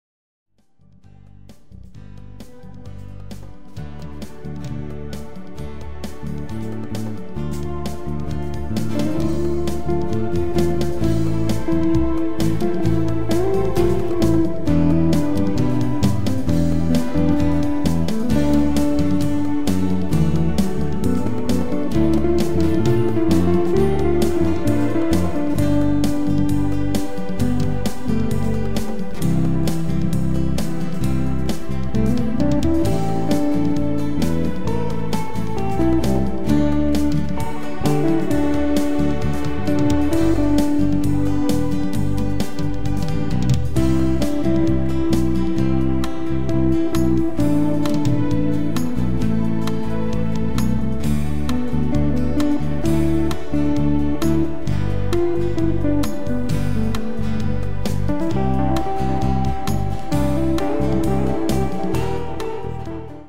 Wieder ein Duett zweier Gitarren.